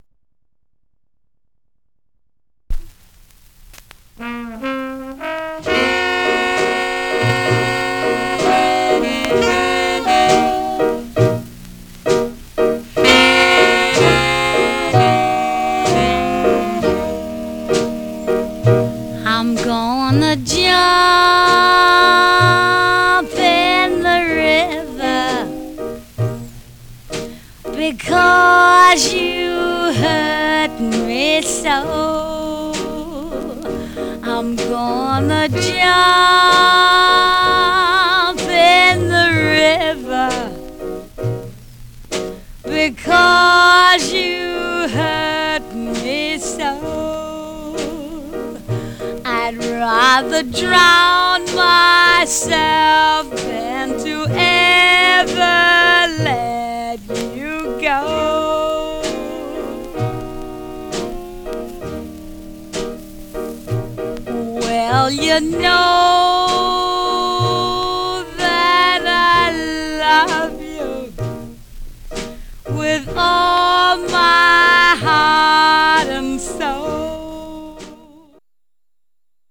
Surface noise/wear Stereo/mono Mono
Rythm and Blues